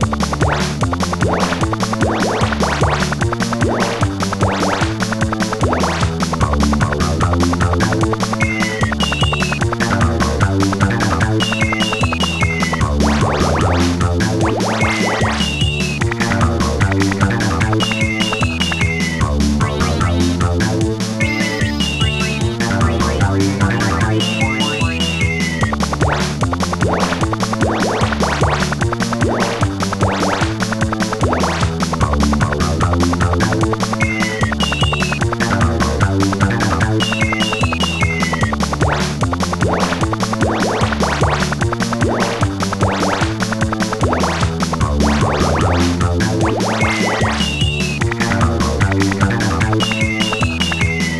Star Tracker/StarTrekker Module
Title comet Type Protracker and family